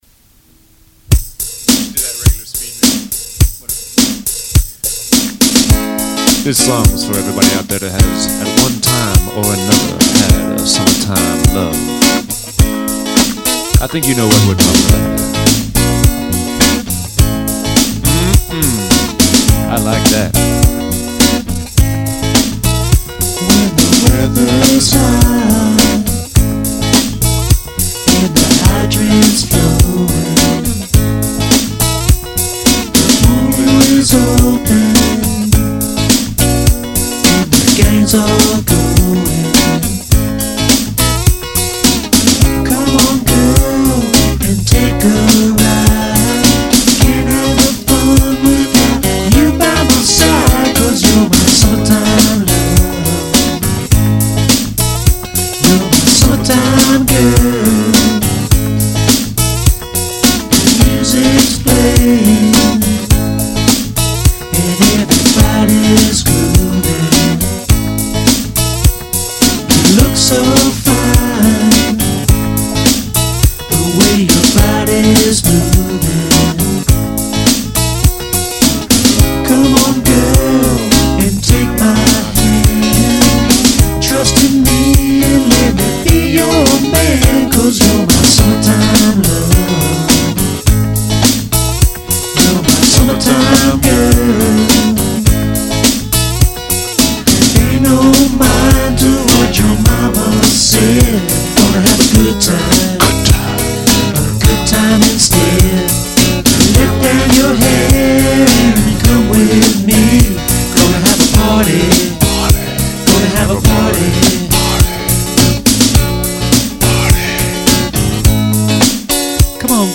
old tape recording
comparing it to Mac DeMarco